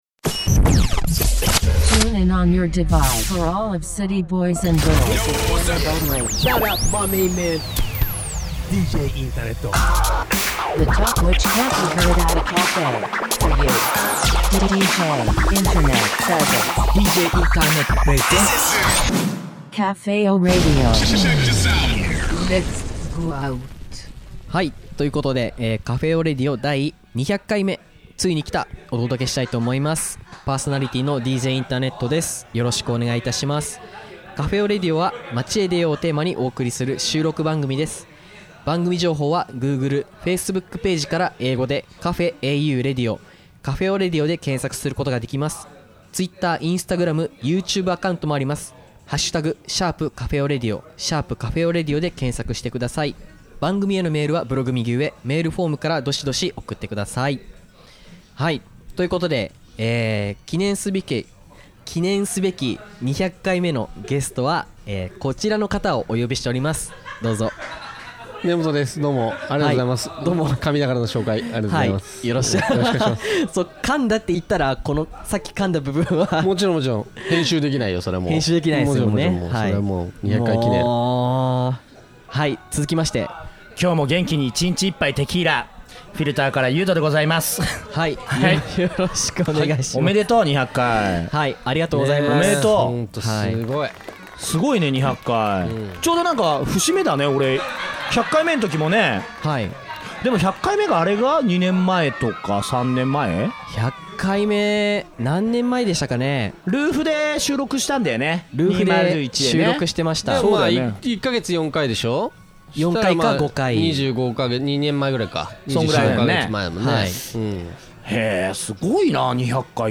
今回はフリートークな感じでお届けしております。 お二人からは、今後の番組制作の展望を聞かれました。